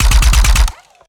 GUNAuto_RPU1 Burst_04_SFRMS_SCIWPNS.wav